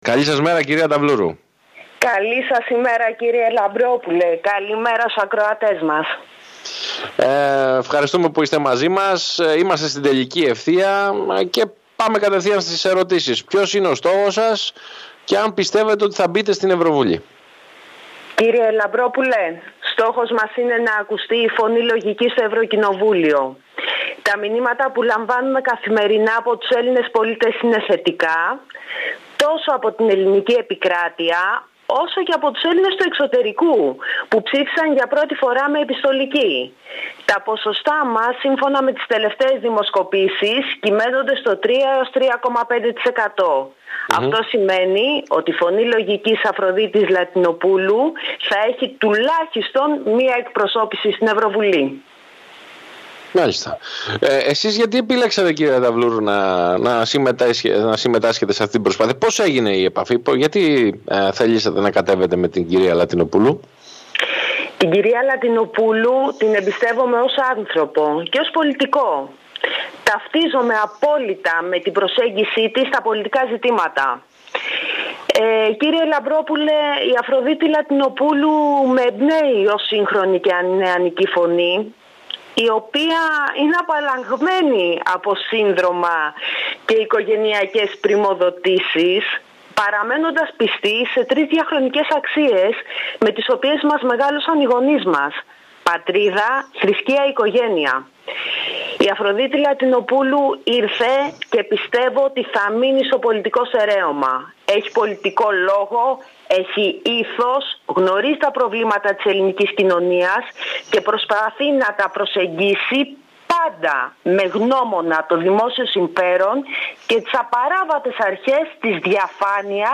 Αναλυτικά η συνέντευξη